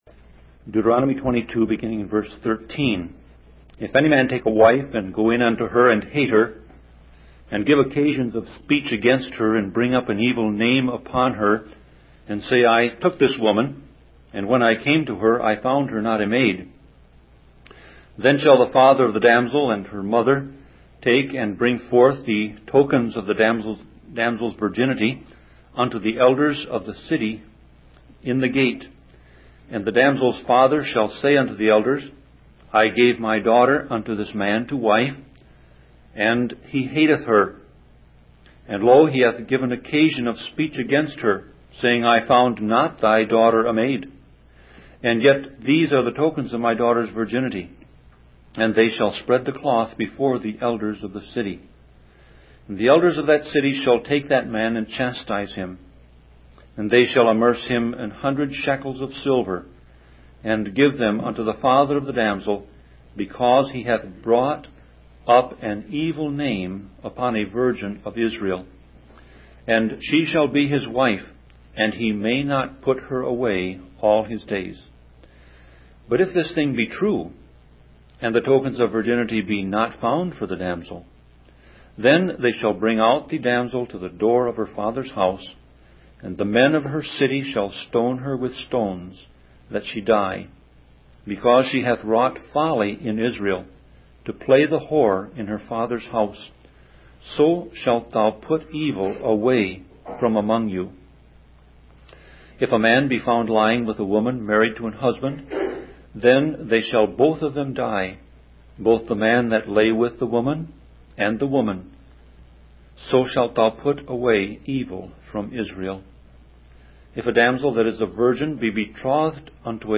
Sermon Audio Passage: Deuteronomy 22:13-30 Service Type